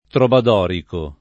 trobadorico [ trobad 0 riko ]